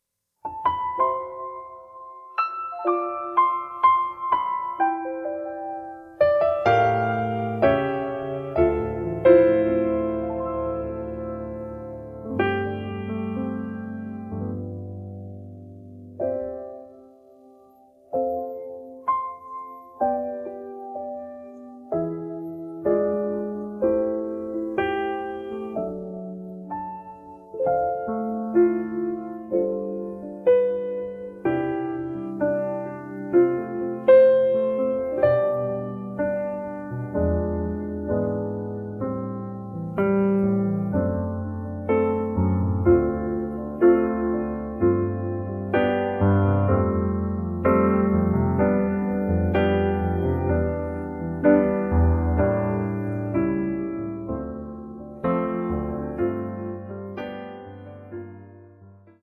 음정 -1키 3:38
장르 가요 구분 Voice Cut